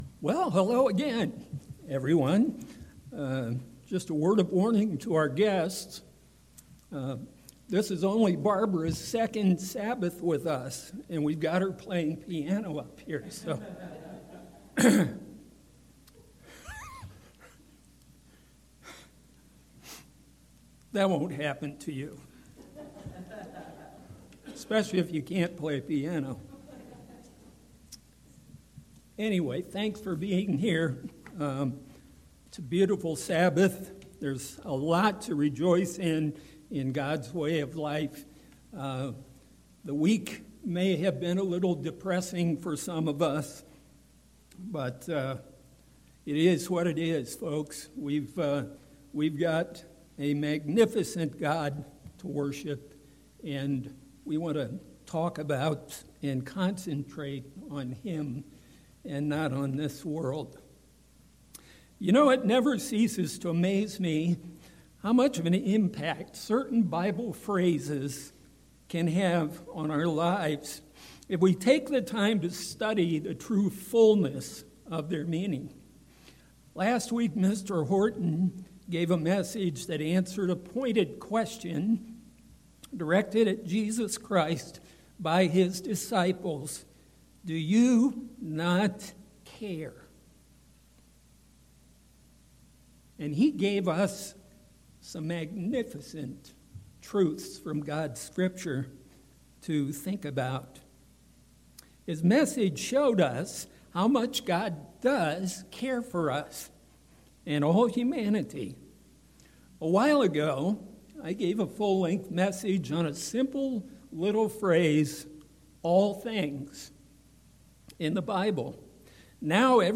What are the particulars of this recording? Given in Mansfield, OH